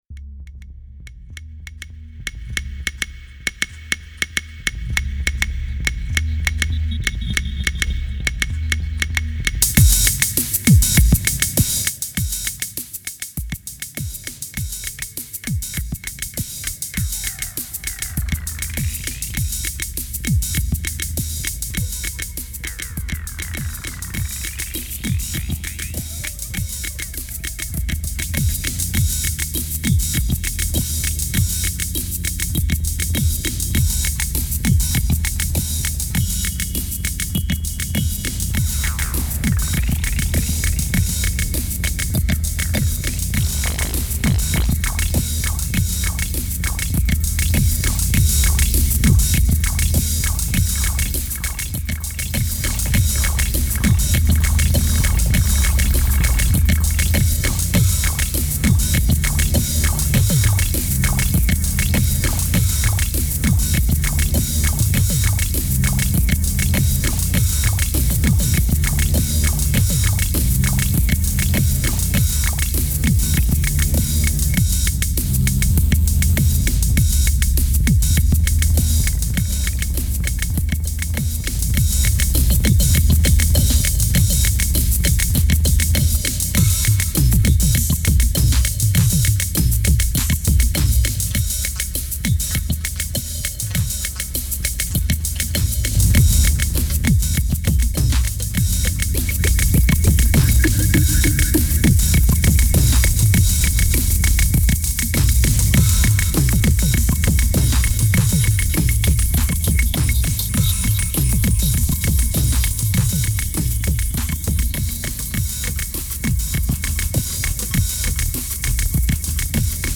2388📈 - -26%🤔 - 100BPM🔊 - 2009-03-20📅 - -684🌟